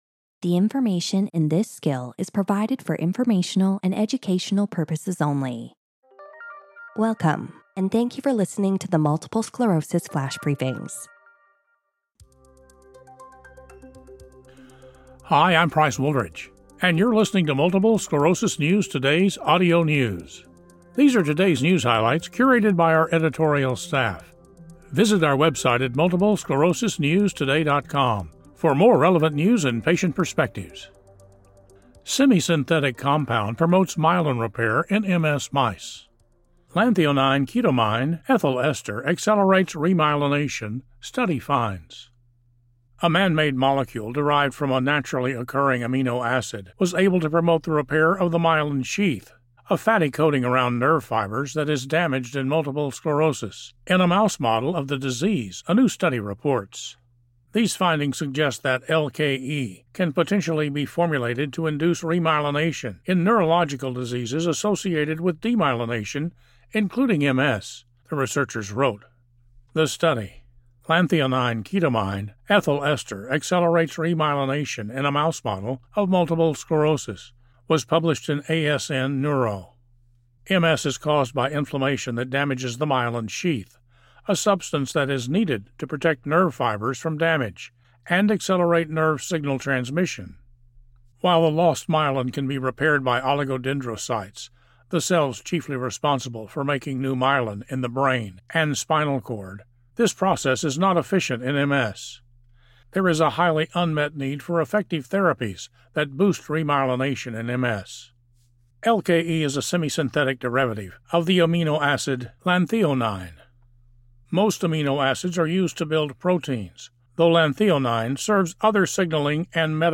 reads the news article on how a man-made molecule was able to promote myelin repair in a mouse model of MS.